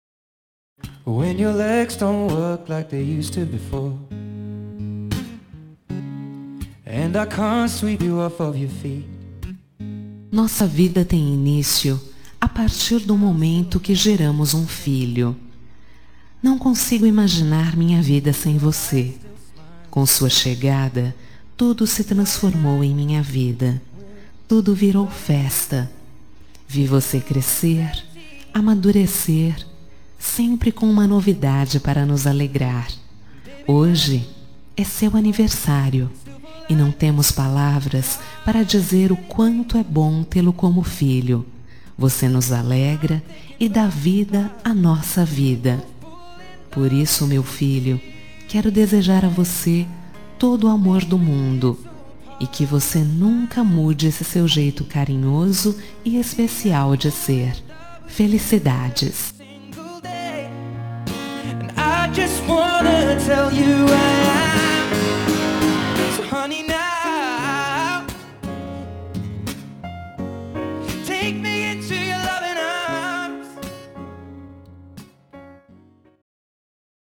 Telemensagem de Aniversário de Filho – Voz Feminina – Cód: 1817